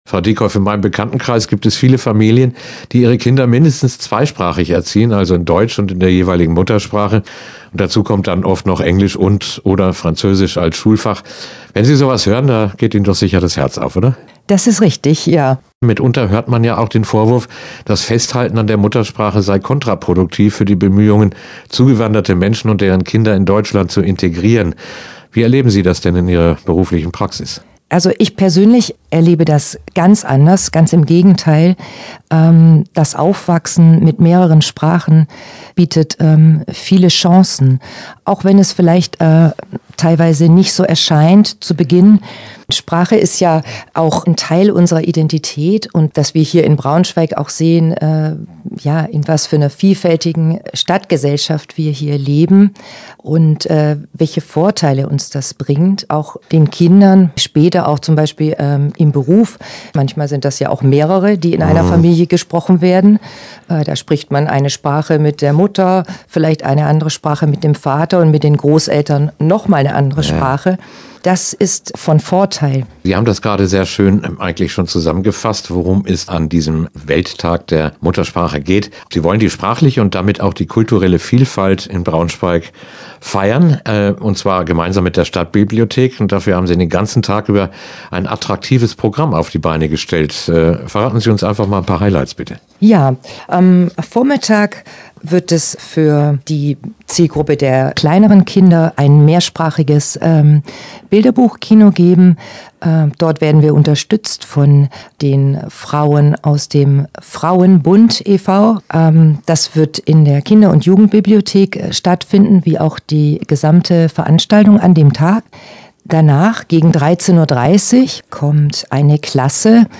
Interview-Tag-der-Muttersprache-2025.mp3